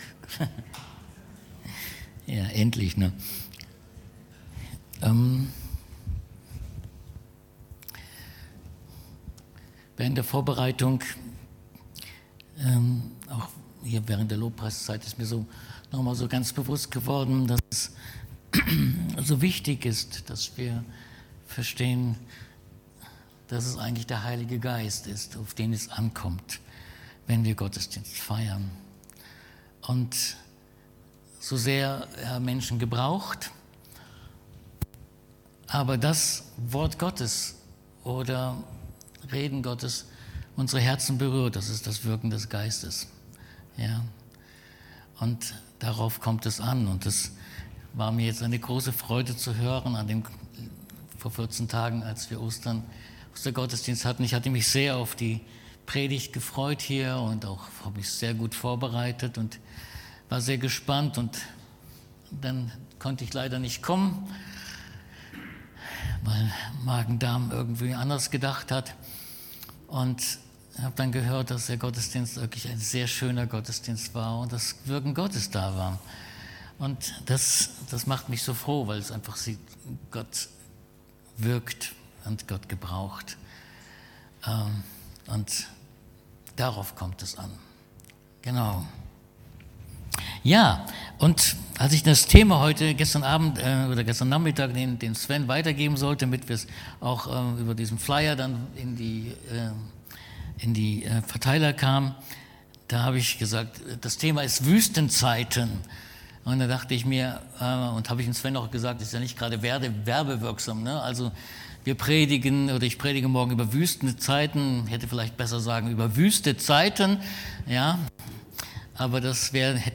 Korinther 3,17-18 Dienstart: Predigt Die „Wüstenzeiten“ im Christentum bezeichnen Phasen der geistlichen Trockenheit, in denen sich ein Gläubiger von Gott entfernt oder innerlich leer fühlt.